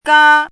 chinese-voice - 汉字语音库
ga1.mp3